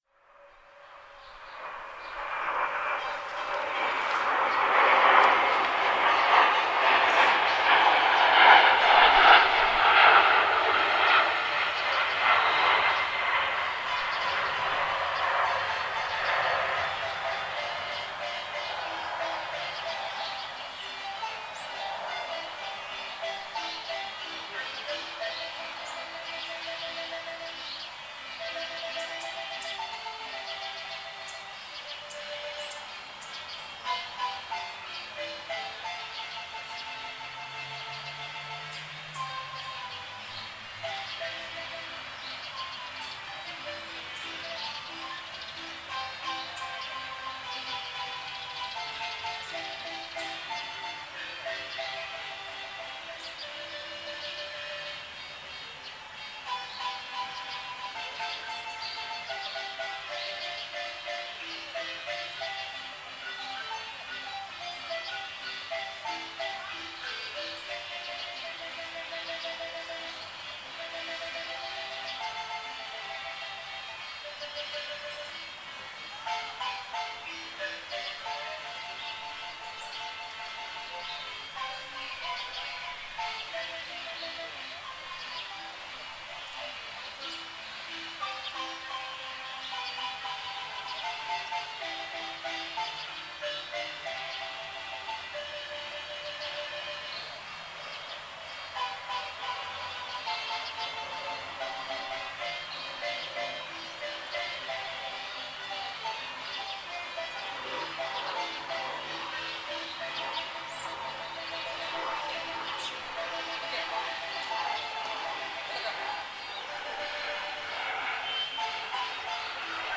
Marimba en la plaza Clemente Orozco
Una marimba ambulante que se detiene a tocar alrededor de la Plaza Clemente Orozco frente a los edificios de condominios habitacionales.
En esta ocasión toca tres melodías: "Solamente una vez" de Agustín Lara, "Pasaste a mi lado" de Ruben Fuentes y "Amor Eterno" de Juan Gabriel; cuando finaliza varias personas salen a sus balcones para darle a los marimbistas su agradecimiento o alguna moneda.
Frecuentemente la marimba visita estas calles, renovando cada vez los sonidos que rodean al parque: las aves, los aviones, los automóviles, los perros que son llevados a pasear, las ambulancias y el movimiento de los árboles.
Mexico Equipo: SONY ICD-UX71 Stereo Fecha